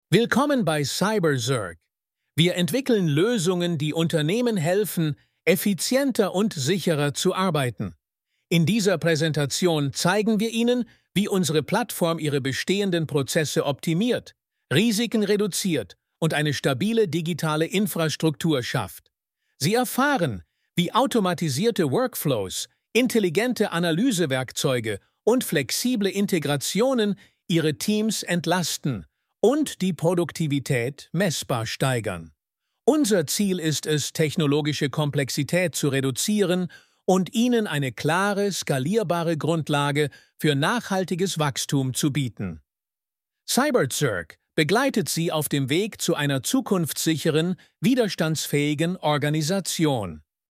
Использованный стиль – «Новости».
Однако при восприятии на слух с учетом того, что язык – чужой, результат звучит естественно и убедительно. Живые интонации, ровный ритм, никаких следов искусственности и машинности.